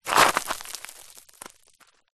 Звуки поскальзывания
Звук оступившегося человека на гравийной поверхности